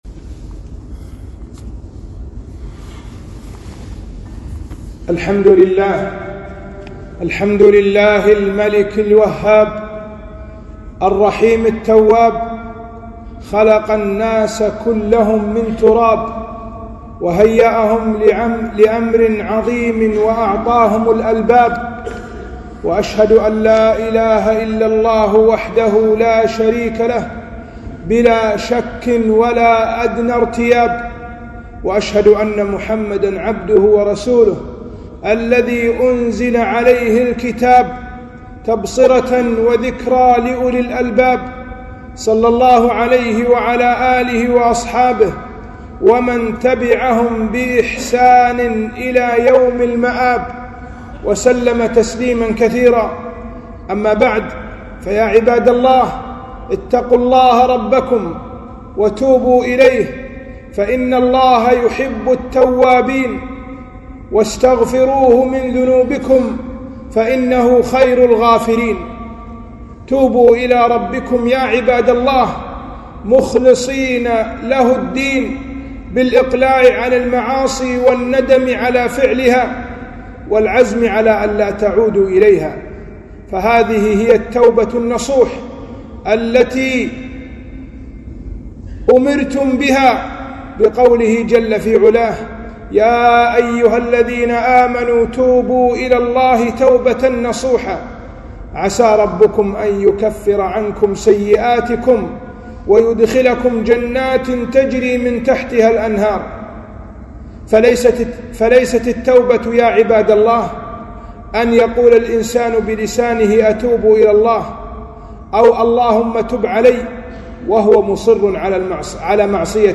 خطبة - التوبة النصوح